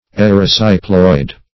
erysipeloid \er`y*sip"e*loid\ ([e^]r`[i^]*s[i^]p"[-e]*loid), n.